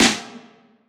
Snares
Boom-Bap Snare 51.wav